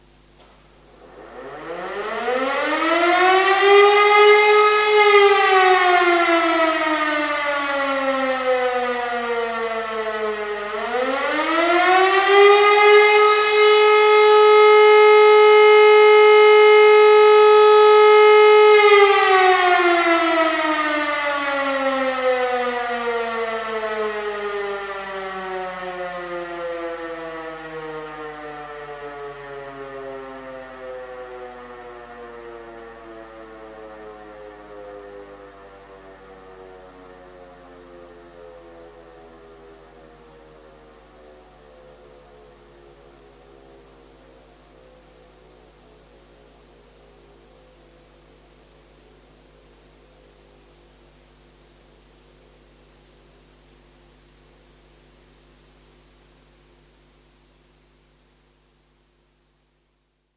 Akuter Luftalarm (Fliegeralarm)
sirenensiegnal-akute-luftgefahr.mp3